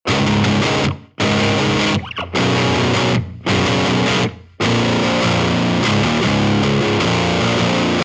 new_frantic_metal.mp3 new_frantic_metal.mp3 251,6 KB · Просмотры: 209 i1.jpg 47,2 KB · Просмотры: 168 i2.jpg 9,8 KB · Просмотры: 168 i3.jpg 21 KB · Просмотры: 170 i4.jpg 40,6 KB · Просмотры: 164